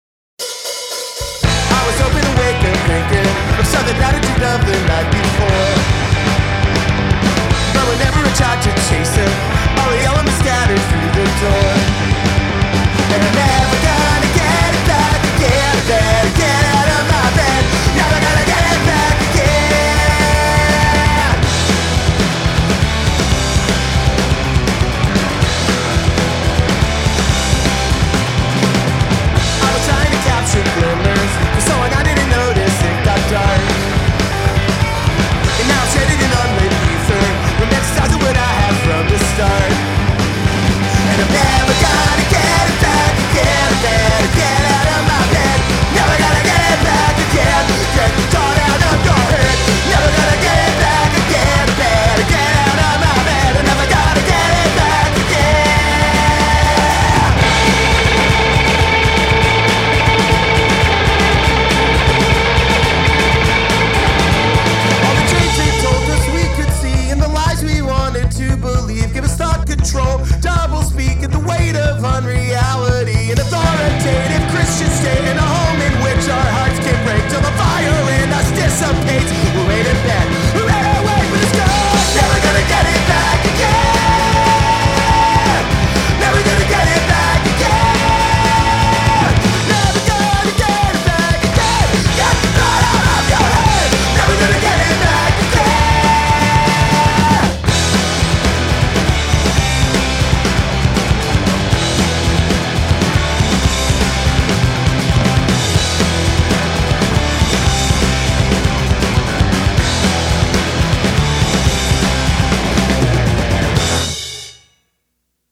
at The Space in Pittsburgh, PA
Mixed, mastered and e-mailed